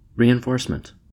Captions English pronunciation of "reinforcement" in American English
Summary Description En-us-reinforcement.ogg English: Audio pronunciation of the word "reinforcement" in U.S. English.
En-us-reinforcement.ogg